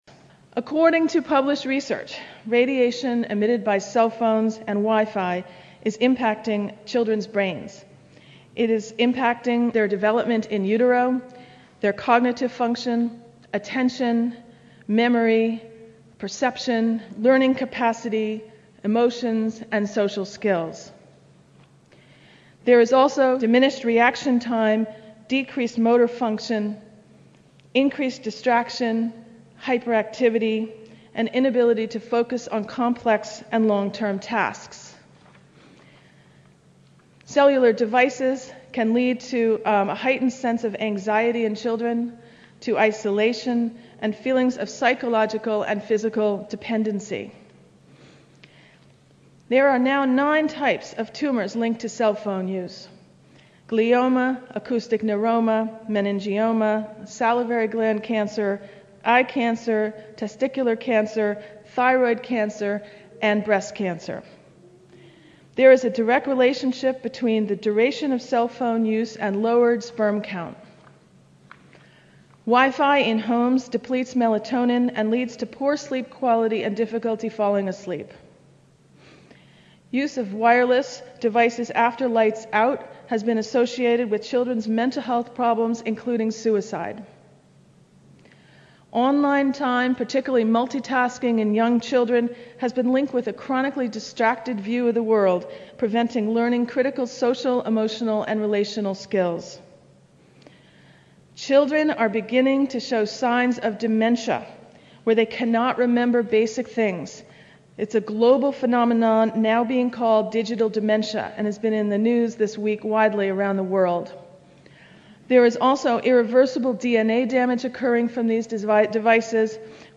Here are some highlights from the expert panel: